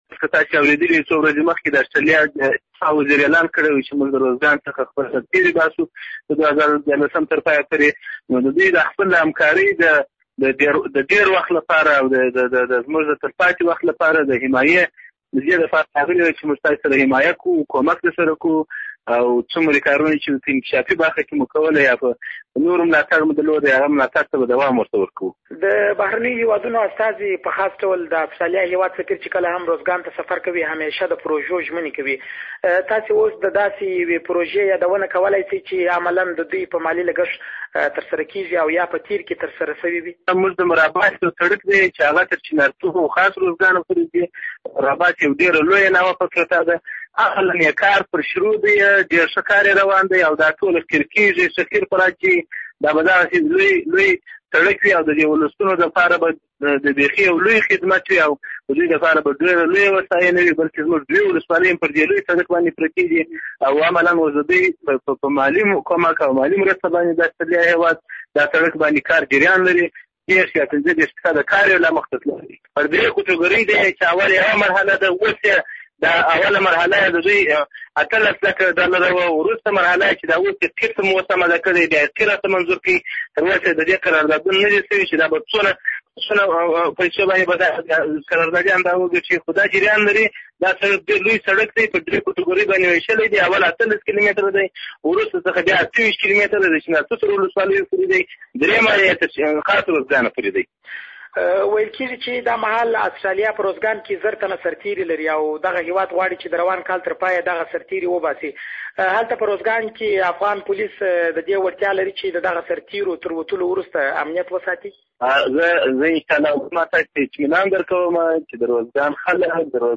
د ارزګان له والي سره مرکه